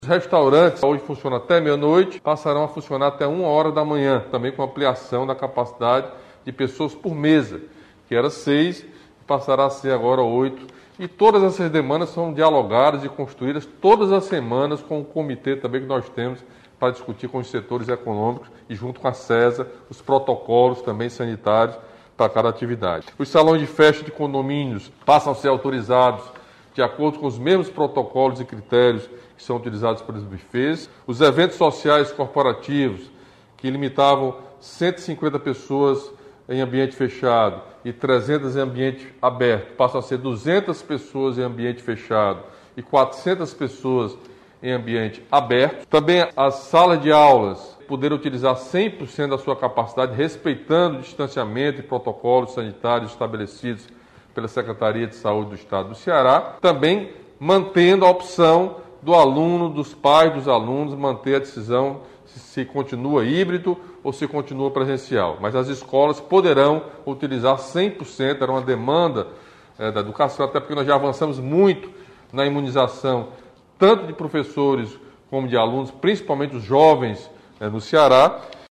Governador Camilo Santana comentou algumas mudanças que passam a valer a partir da próxima segunda-feira (20/09) até o próximo dia 3 de outubro.